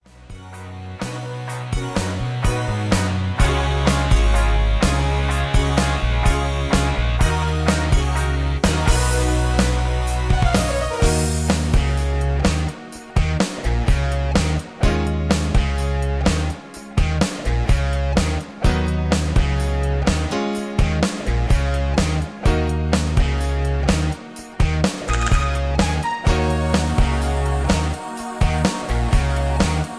Tags: karaoke , mp3 backing tracks